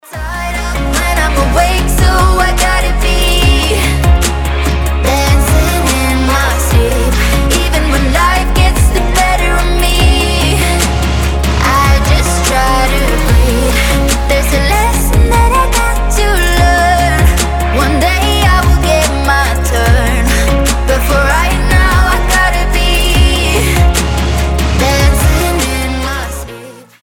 • Качество: 320, Stereo
поп
мелодичные
красивый женский голос